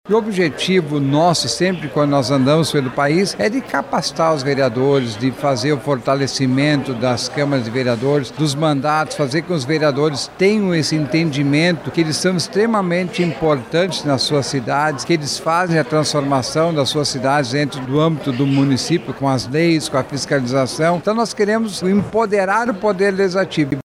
A posse da diretoria da União dos Vereadores do Brasil – Seccional Amazonas ocorreu na manhã desta sexta-feira 19/09, durante uma cerimônia solene, na Câmara Municipal de Manaus – CMM.
SONORA-1-POSSE-DIRETORIA-UVB.mp3